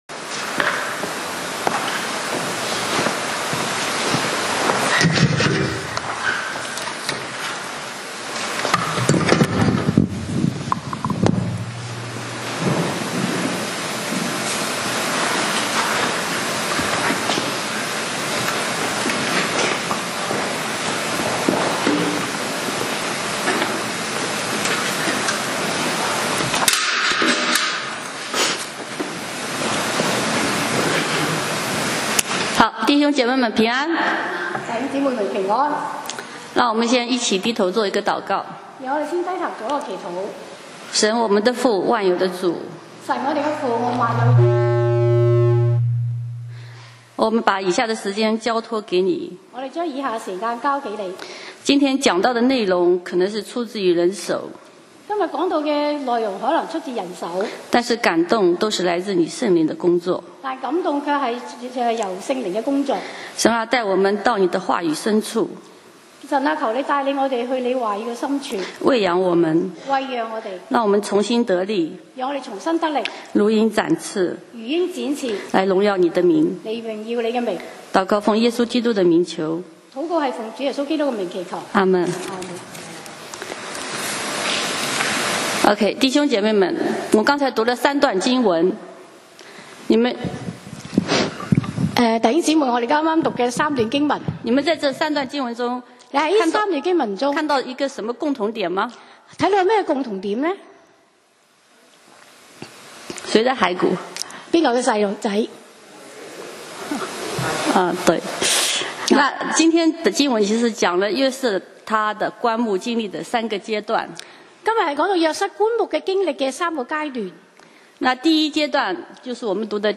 講道 Sermon 題目 Topic：在棺木与应许之间 經文 Verses：創世紀 Genesis 50：24-26 出埃及記 Exodus 13:18-19 約書亞記 Joshua 24:32。